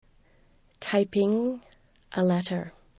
typing (a letter)   tɑɪpIŋ